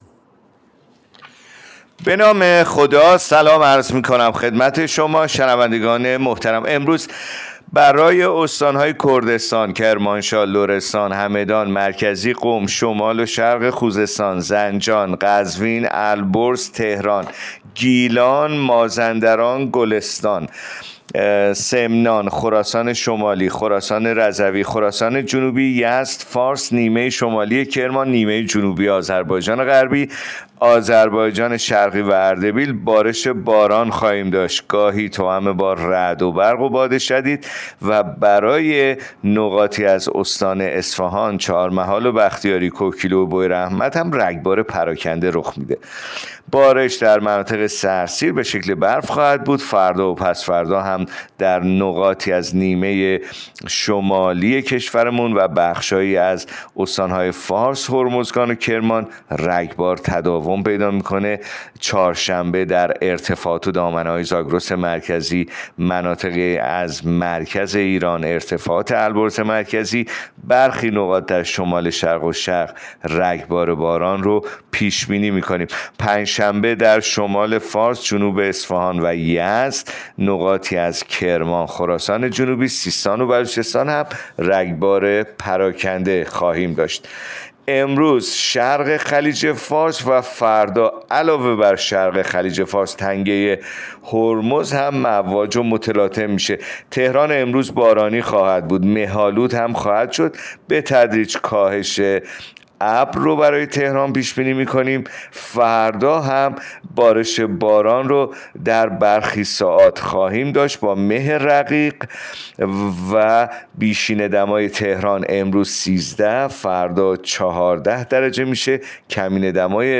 گزارش رادیو اینترنتی پایگاه‌ خبری از آخرین وضعیت آب‌وهوای ۱۹ اسفند؛